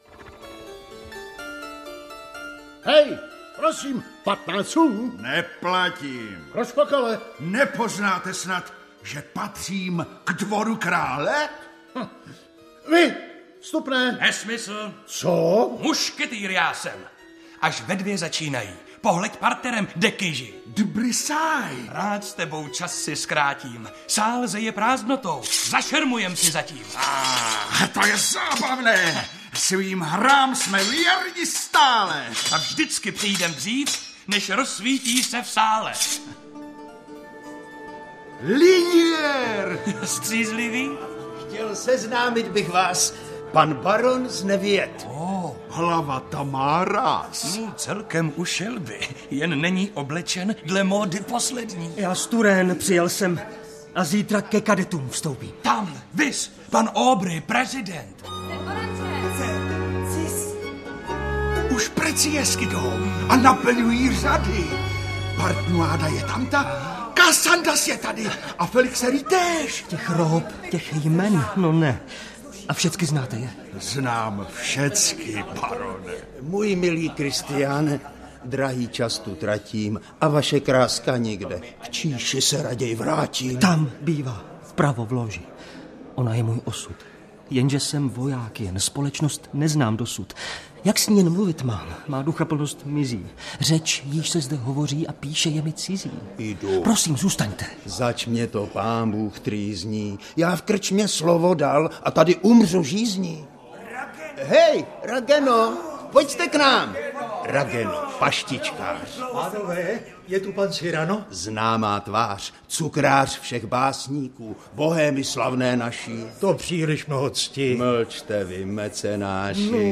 Audiokniha Cyrano z Bergeracu - obsahuje rozhlasovou hru podle předlohy, jejímž autorem je Edmond Rostand.